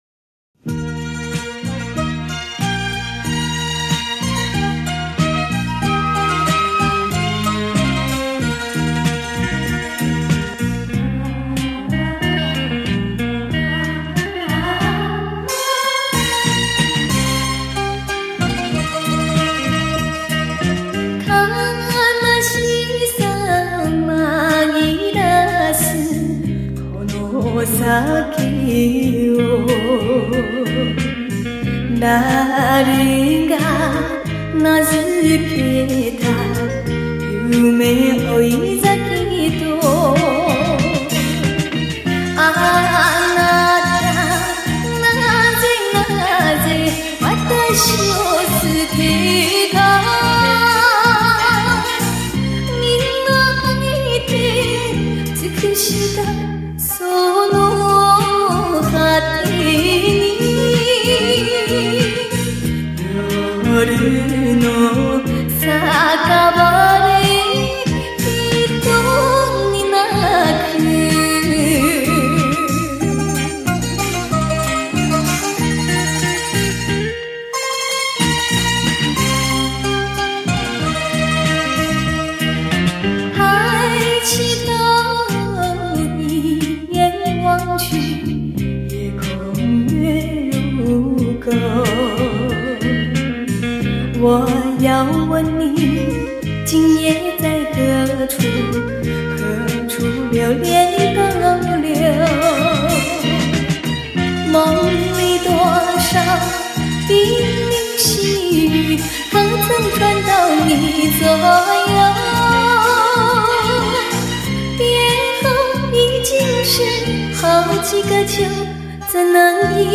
音樂類型 : 華語  [CD 專輯]